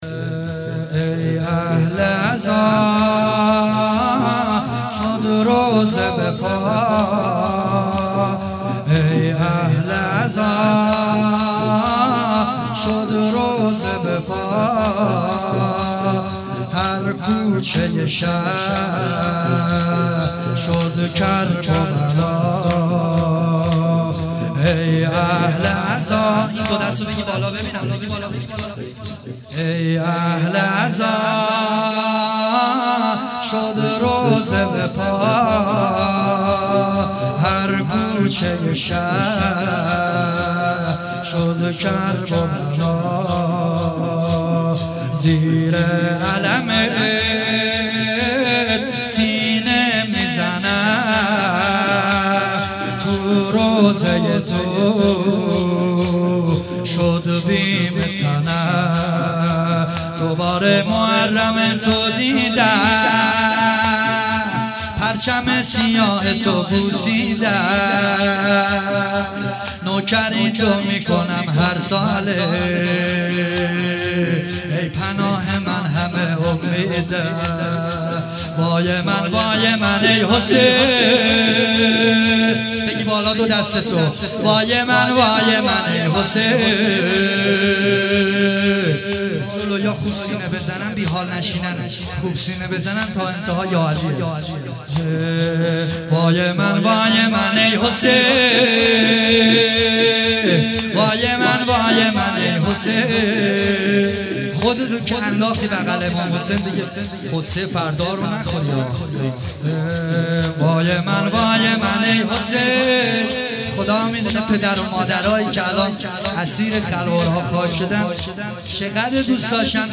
نوحه شور ای اهل عزا